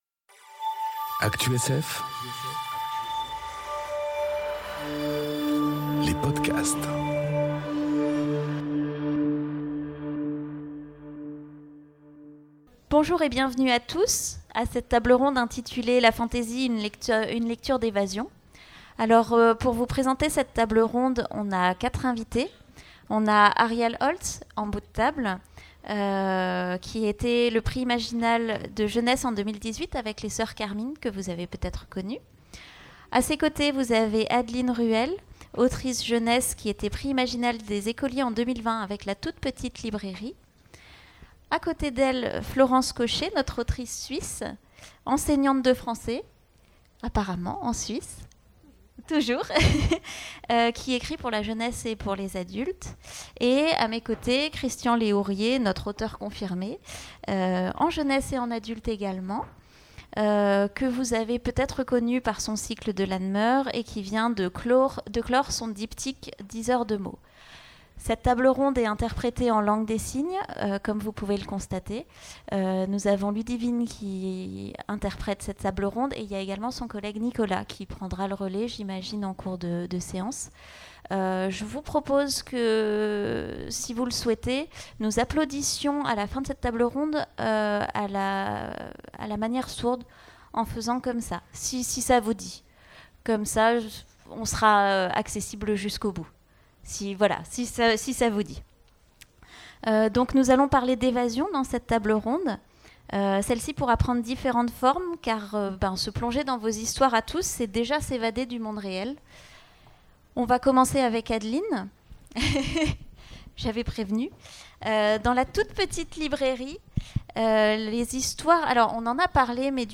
La conférence des Imaginales qui interroge la réputation de littérature d'évasion de l'imaginaire